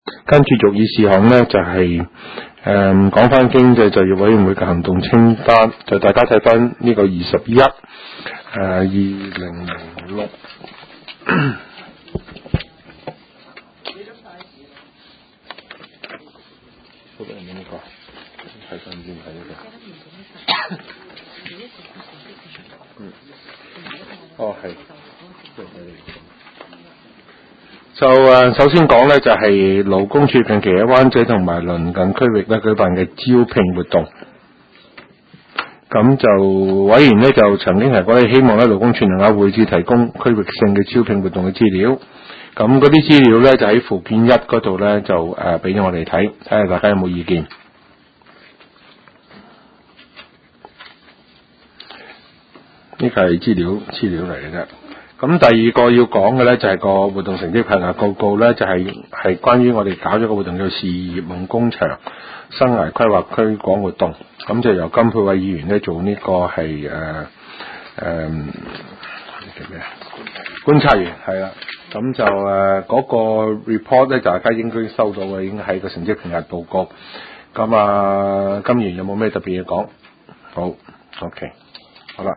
經濟及就業委員會第十八次會議
灣仔民政事務處區議會會議室